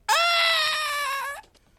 male scream
描述：male screaming
标签： boy guy male screaming
声道立体声